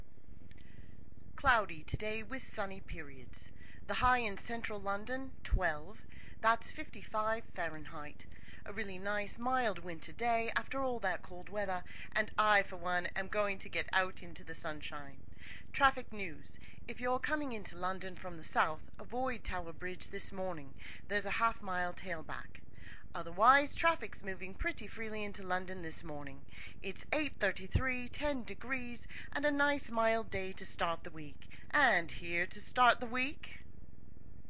英国口音。
Tag: 云-9 公告 英国 口音 伦敦 无线电